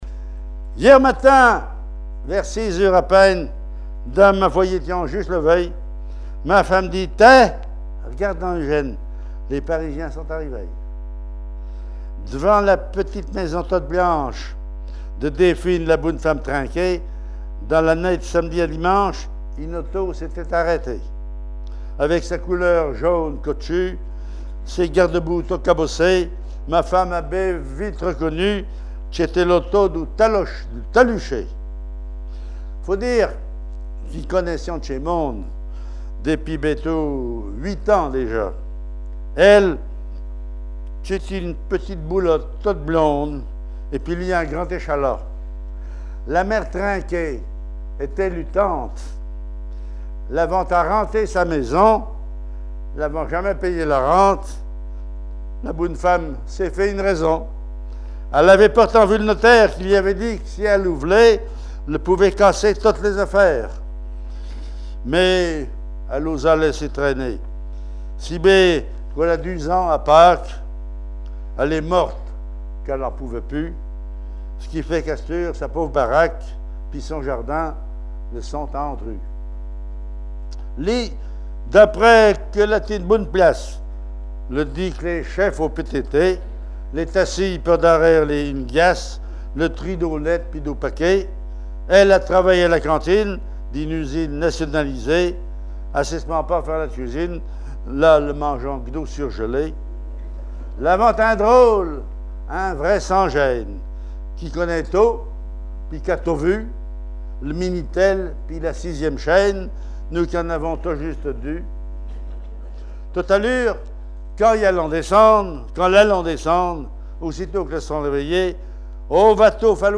Langue Patois local
Genre poésie
Catégorie Récit